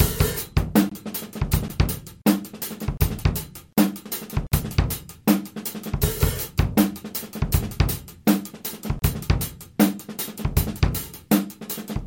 原声大鼓安德森帕克（Anderson Paak）类型的节拍与幽灵音符
描述：安德森帕克（Anderson Paak）类型的节拍在原声鼓上录制，用Audacity制作样本。